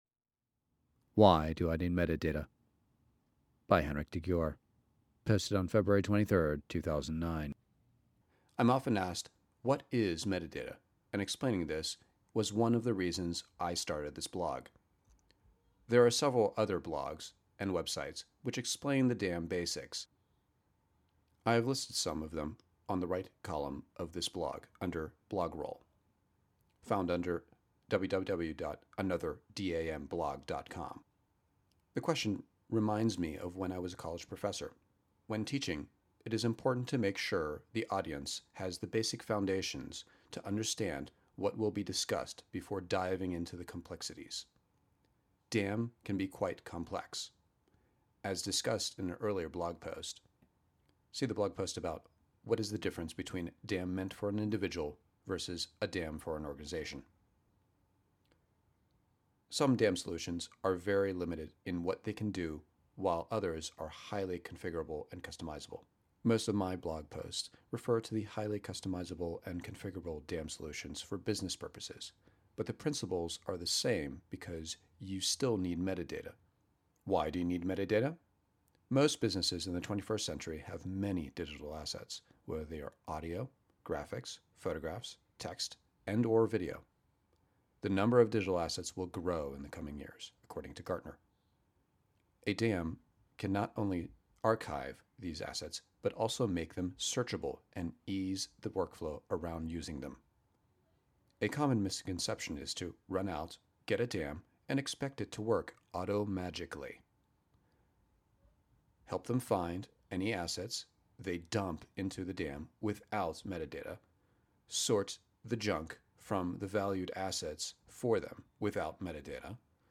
Written and read